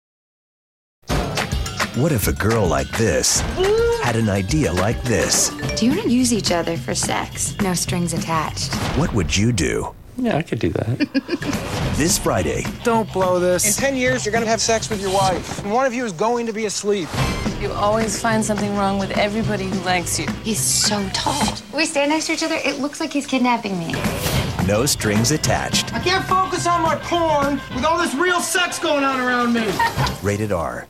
No Strings Attached TV Spots